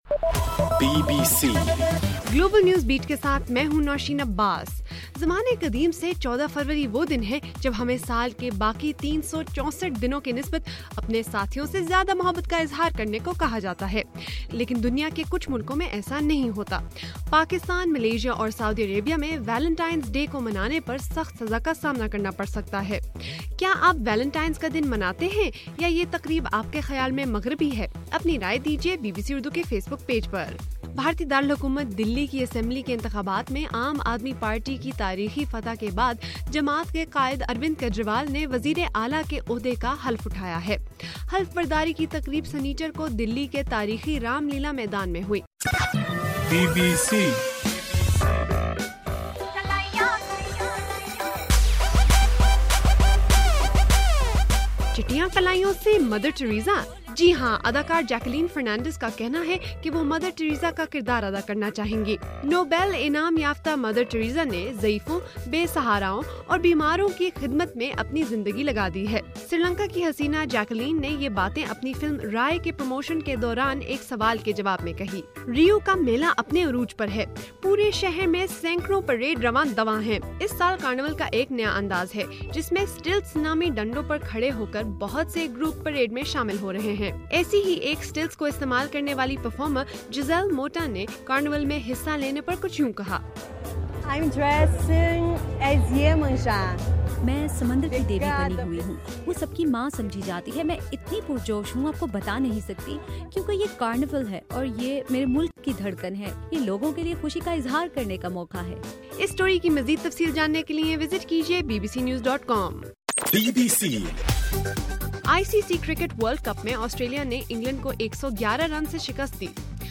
فروری 15: صبح 1 بجے کا گلوبل نیوز بیٹ بُلیٹن